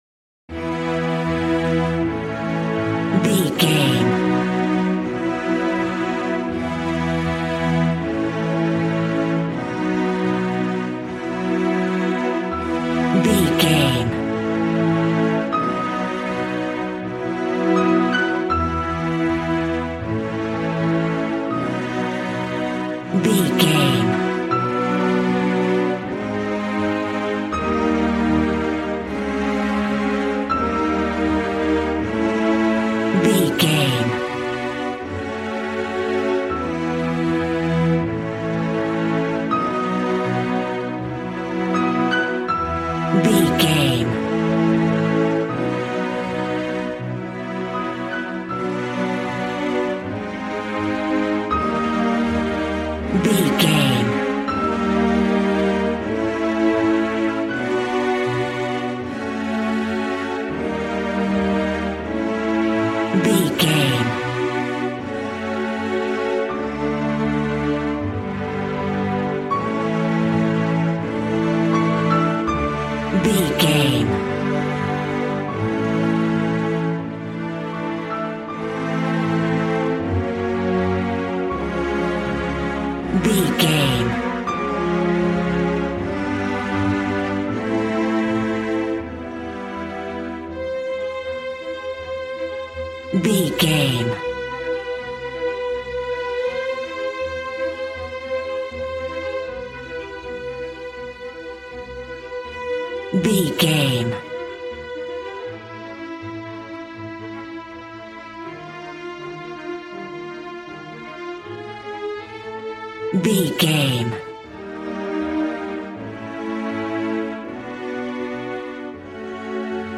Ionian/Major
regal
cello
violin
strings